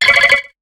Cri de Charmillon dans Pokémon HOME.